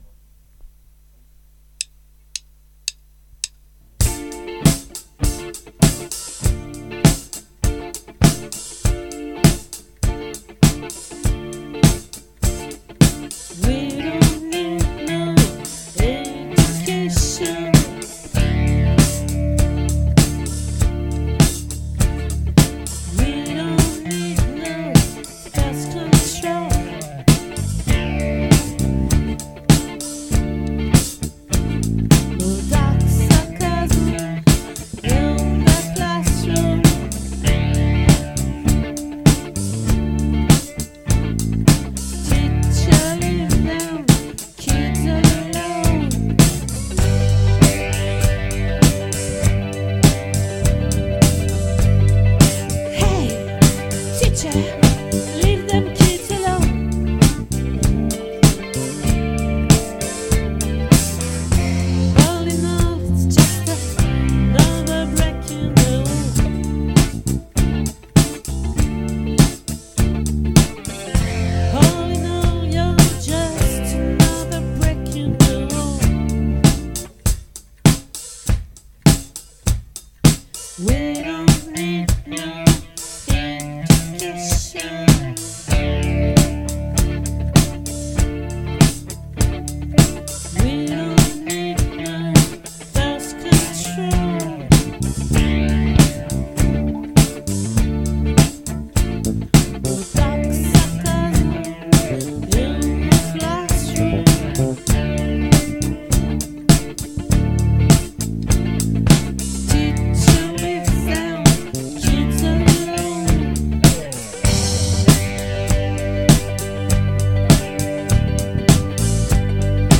🏠 Accueil Repetitions Records_2023_04_19